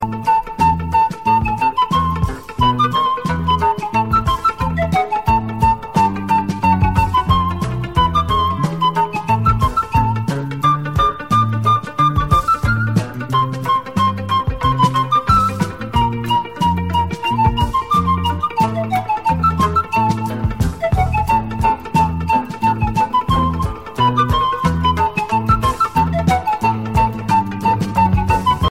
Baby